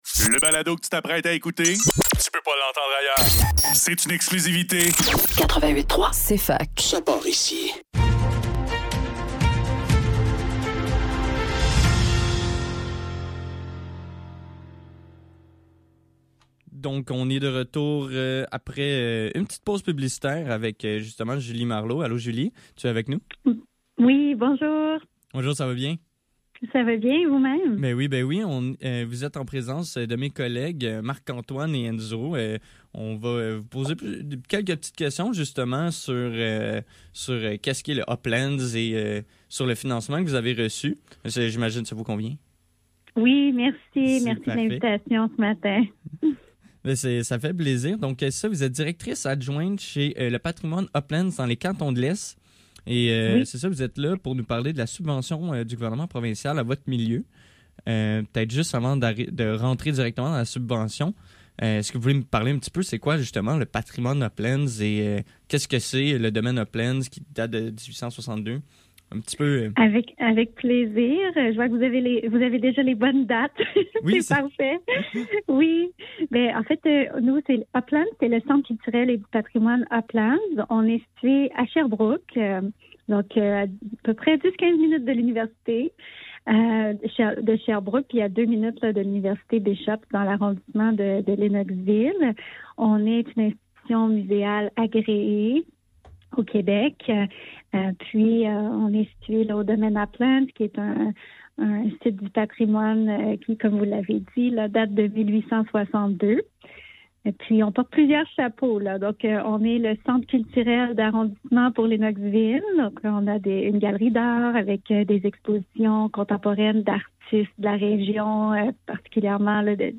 Le NEUF Le NEUF - Entrevue